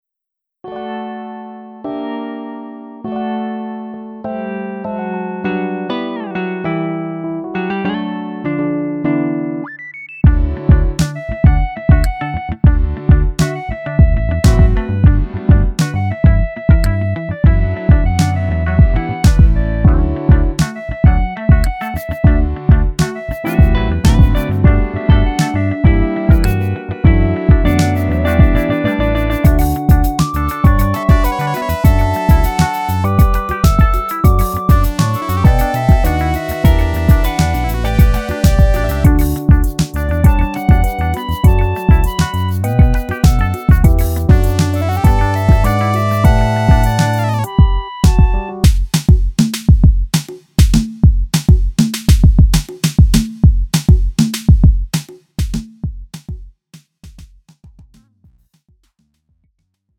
음정 원키 2:19
장르 가요 구분 Lite MR
Lite MR은 저렴한 가격에 간단한 연습이나 취미용으로 활용할 수 있는 가벼운 반주입니다.